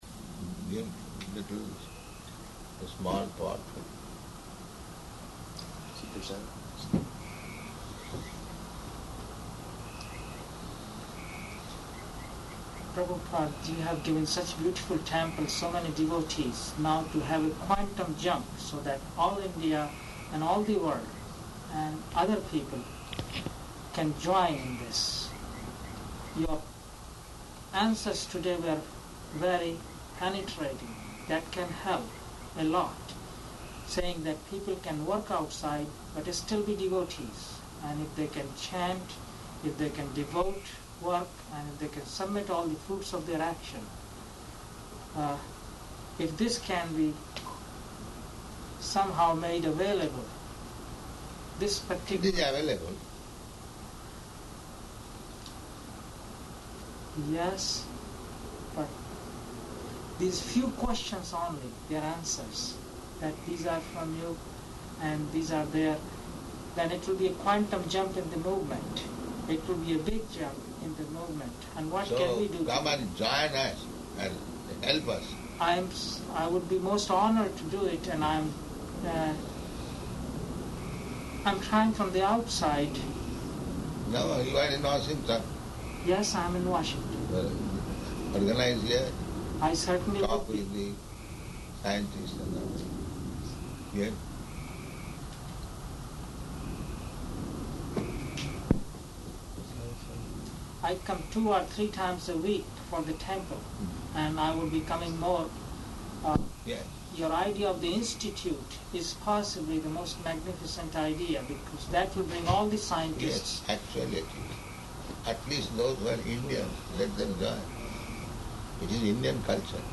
-- Type: Conversation Dated: July 8th 1976 Location: Washington, D.C. Audio file